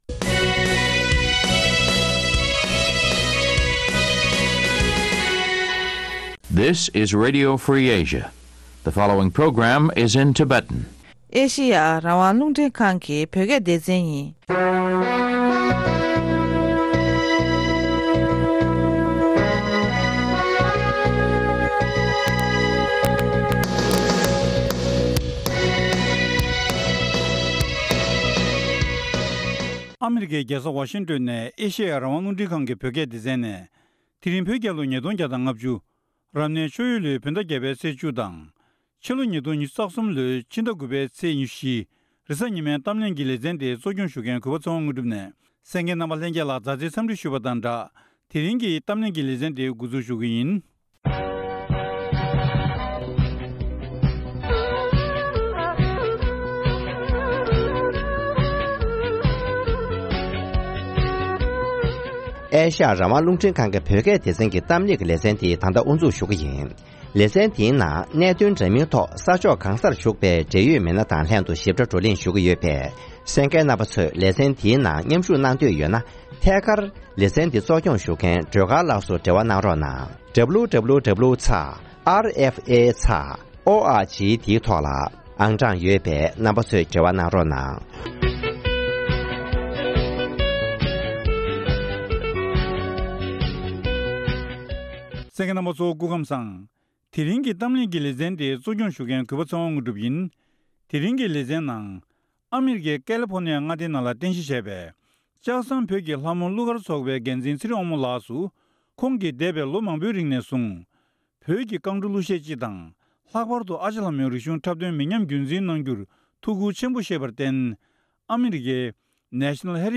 ལྕག་ཟམ་བོད་ཀྱི་ལྷ་མོ་གླུ་གར་ཚོགས་པའི་རྒྱབ་ལྗོངས་ལོ་རྒྱུས་དང་ཨ་རི་རྒྱལ་ཡོངས་སྒྱུ་རྩལ་ཐེབས་རྩ་ཁང་གིས་སྒྱུ་རྩལ་གྱི་གཟེངས་རྟགས་ཤིག་འབུལ་གཏན་འཁེལ་ཡོད་པའི་སྐོར་བཅར་འདྲི་ཞུས་པ།